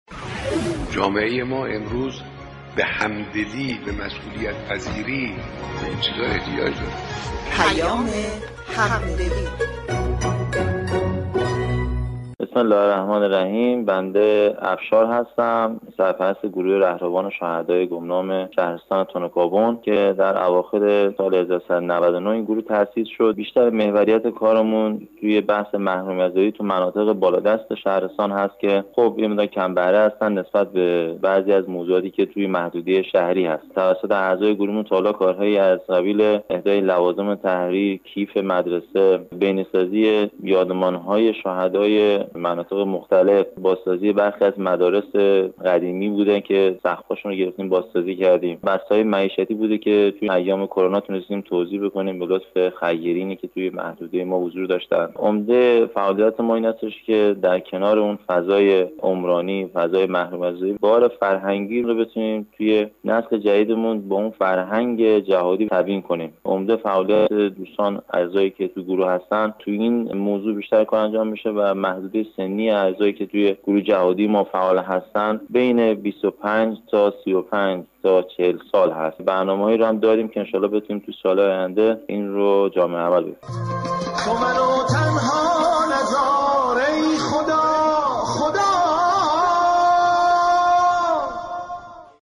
گفتگوی رادیویی؛